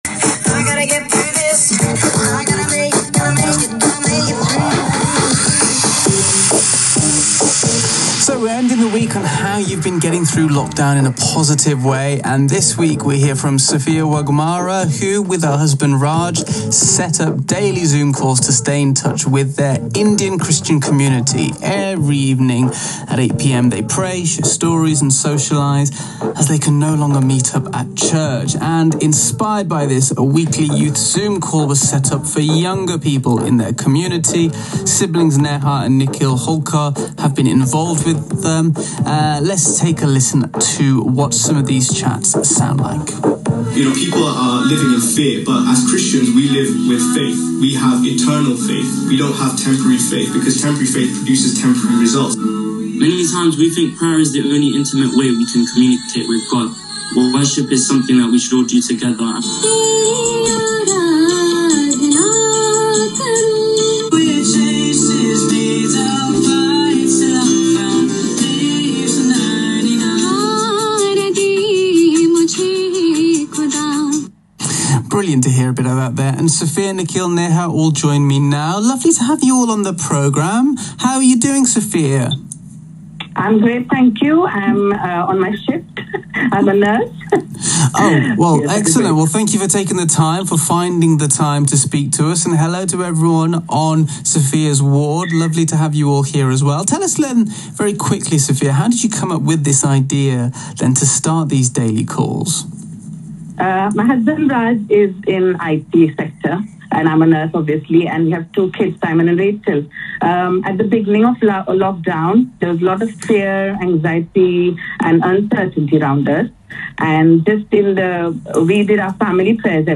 They were recently interviewed on Radio's BBC Asian Network - Recording here.